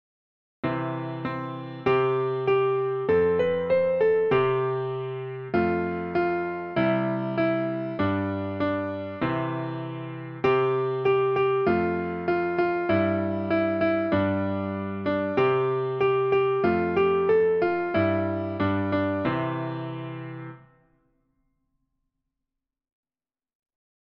Nursery Rhymes:
for piano